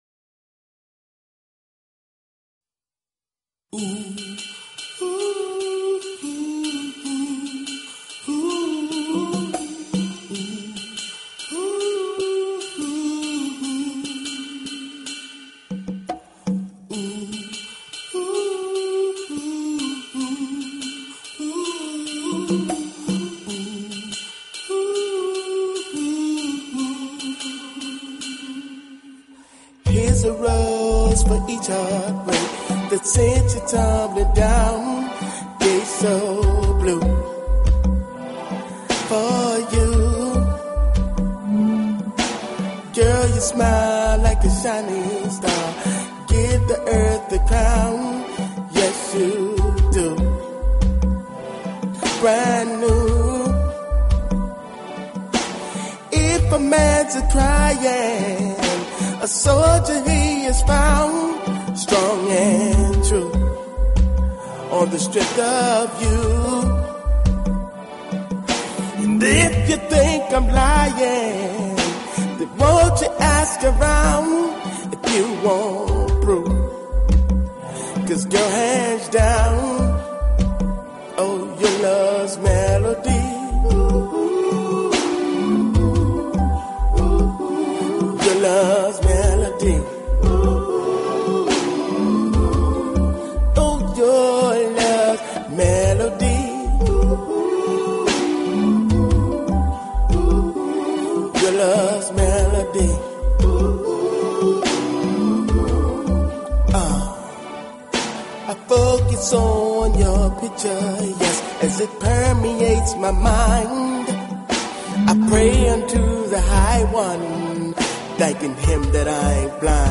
Talk Show Episode, Audio Podcast, Talking_with_the_Gs and Courtesy of BBS Radio on , show guests , about , categorized as